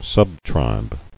[sub·tribe]